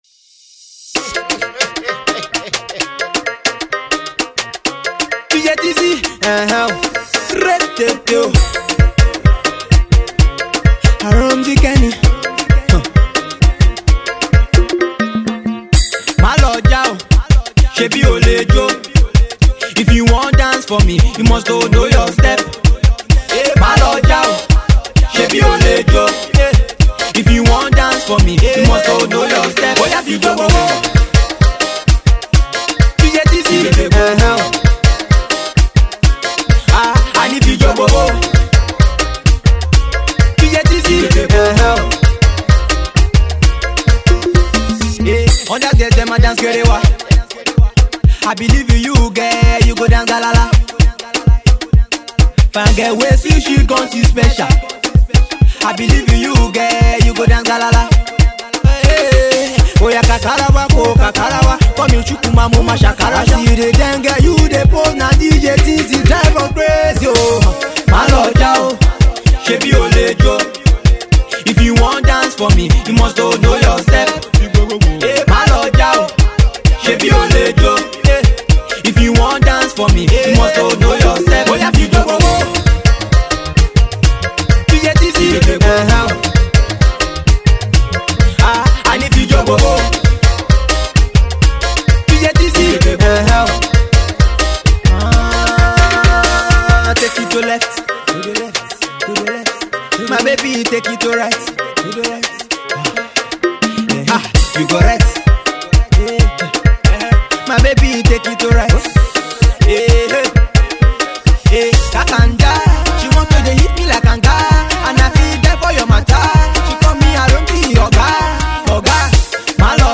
underground pop